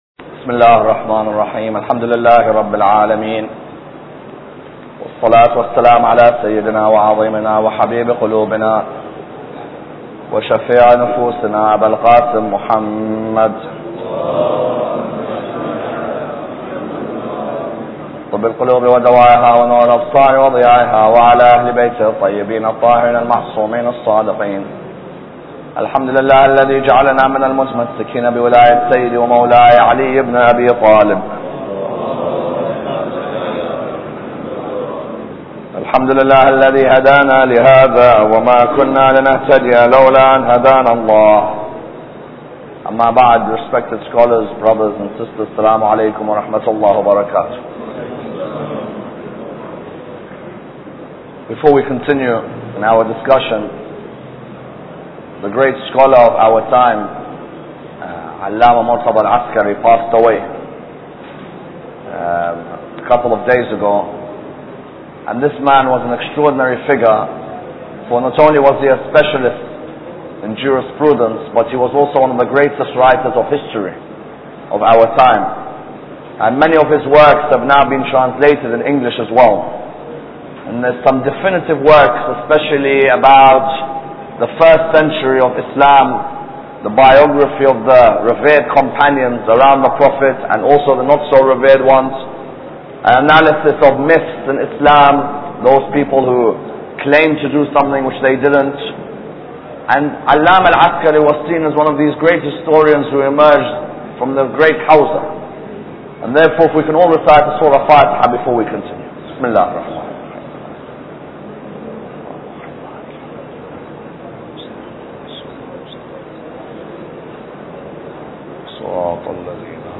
Lecture 6